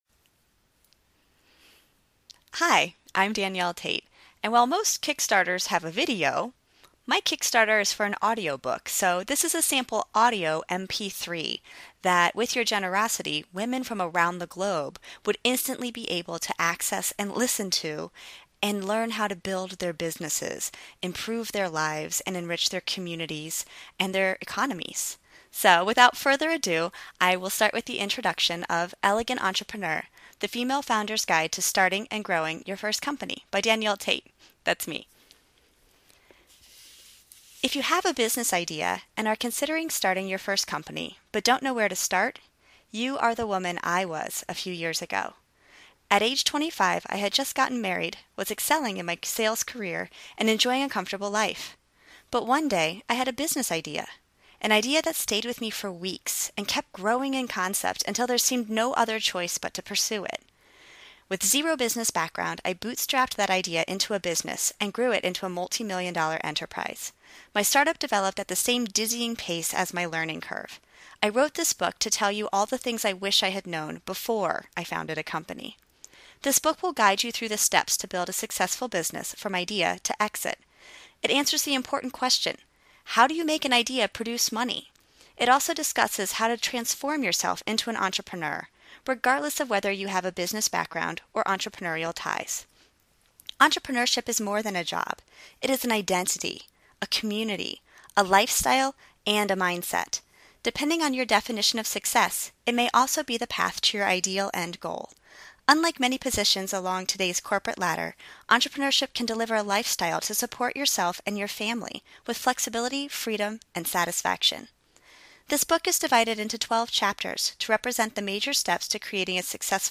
She raised just over $3,000, booked time in a recording studio and set to work.